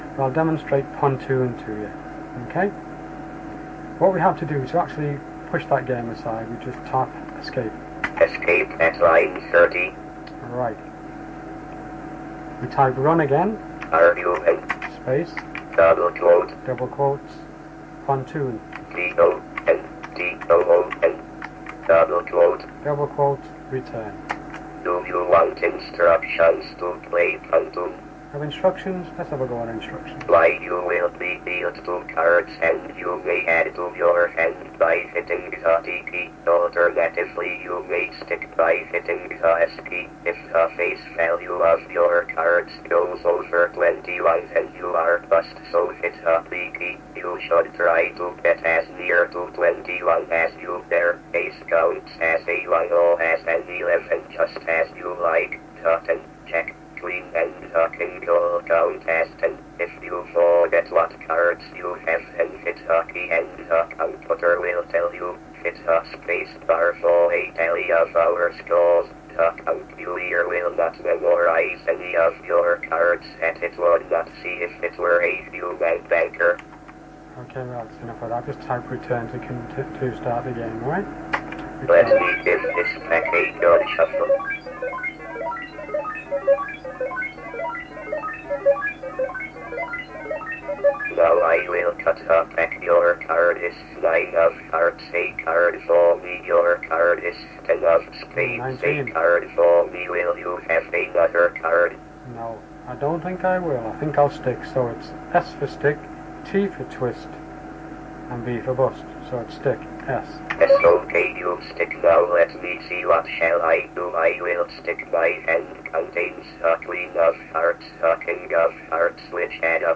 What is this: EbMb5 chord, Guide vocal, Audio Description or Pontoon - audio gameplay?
Pontoon - audio gameplay